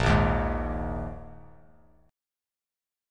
A#7sus4 chord